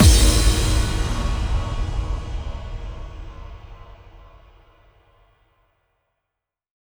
VTS1 Incast Kit Sound FX
VTS1 Incast Kit 140BPM ImpactFx.wav